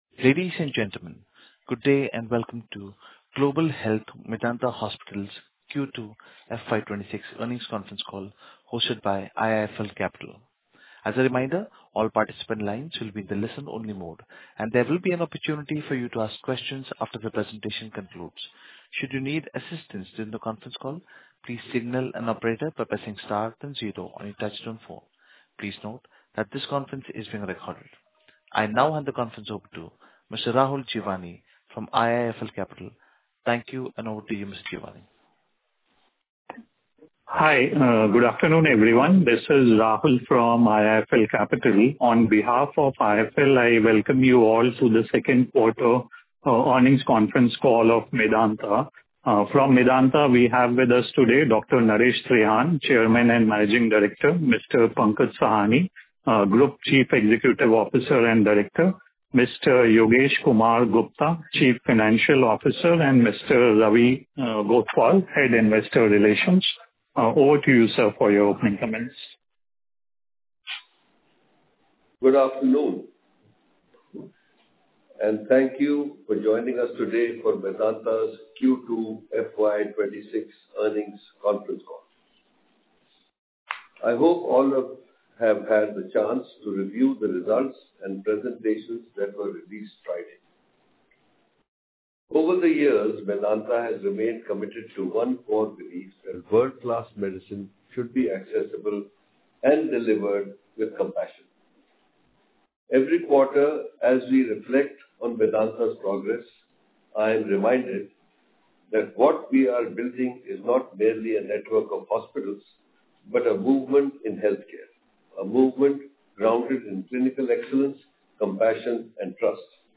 Medanta Earnings Call